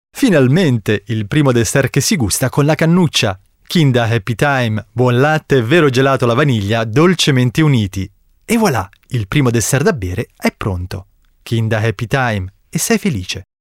Sprecher italienisch.
Kein Dialekt
Sprechprobe: eLearning (Muttersprache):